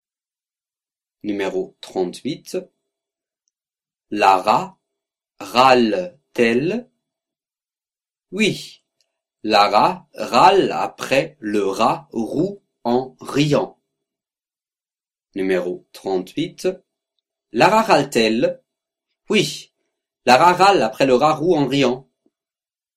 38 Virelangue